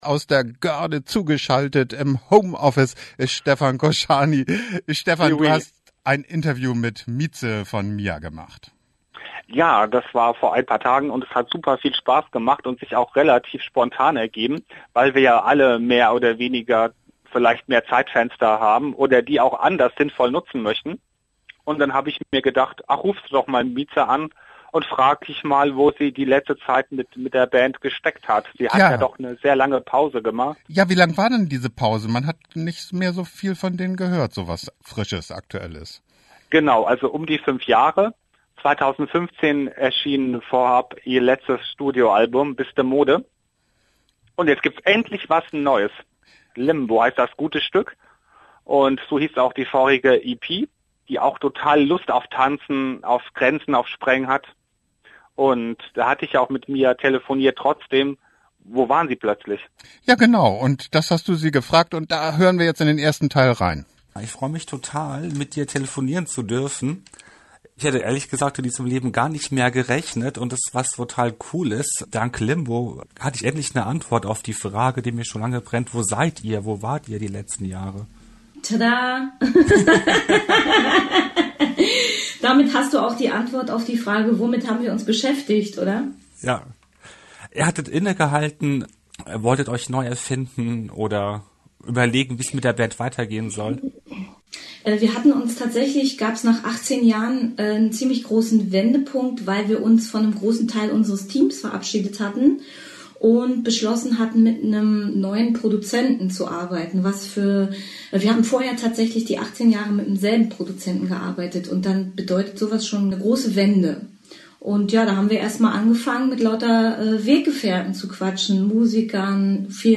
Mieze von Mia im Interview - Teil 1